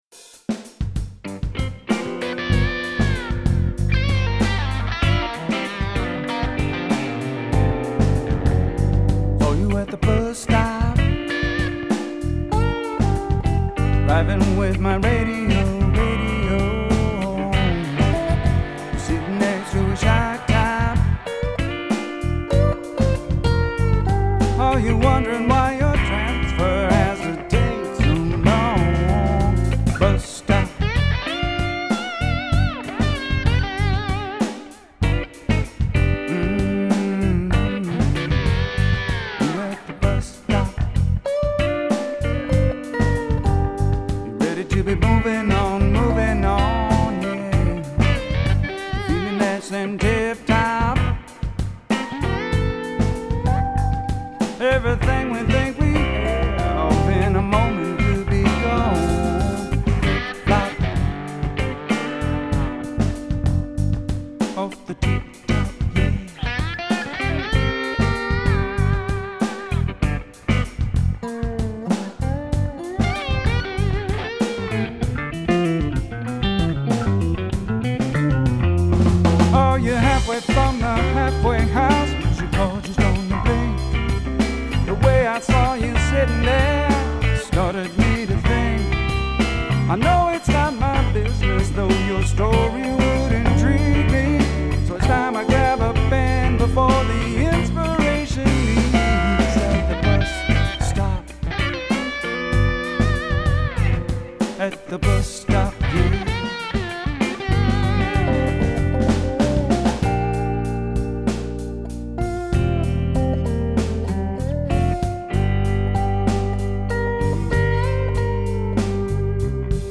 Bus Stop, lots of guitar
This is a mix in progress for your critique. It feels a little disjointed to me in parts and I know it's long.